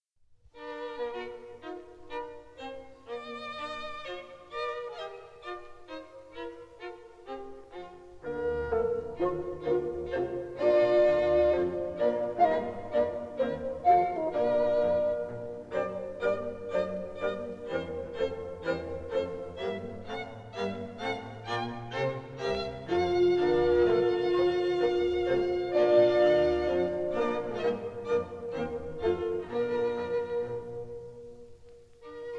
This is a 1959 stereo recording